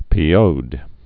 (pēōd)